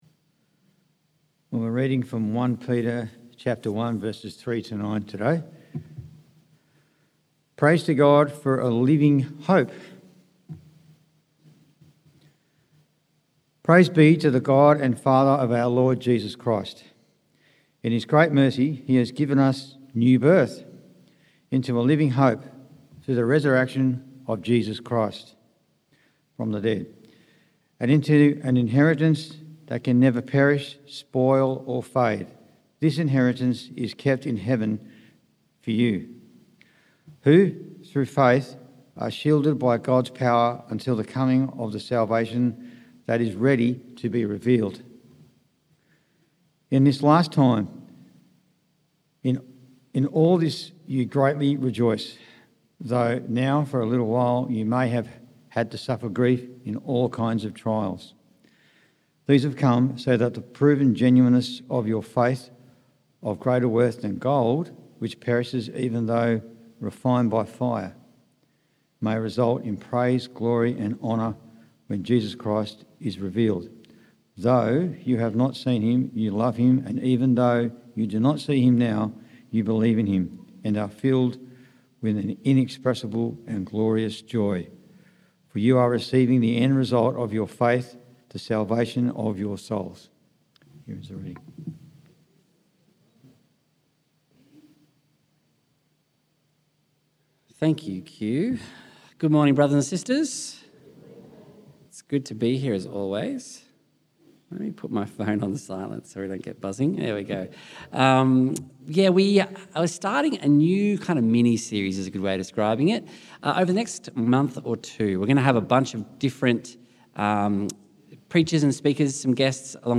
September Sermons